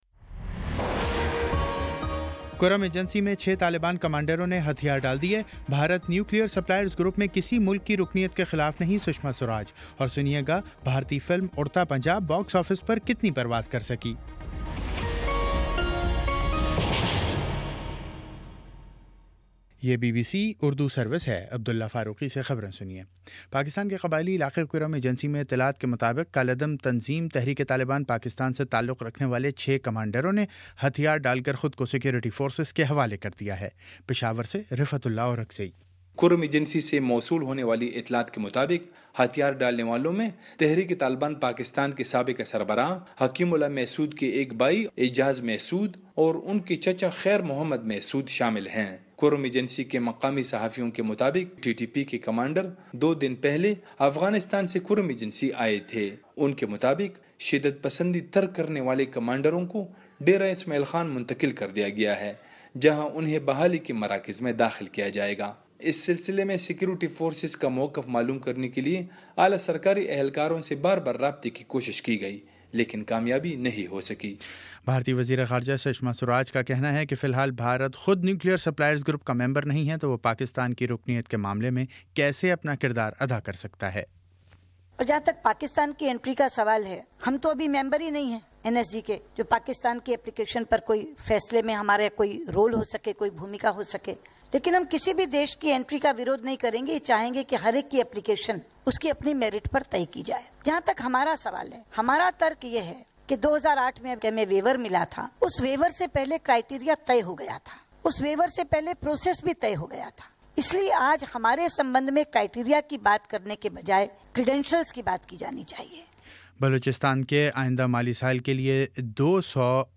جون 19 : شام چھ بجے کا نیوز بُلیٹن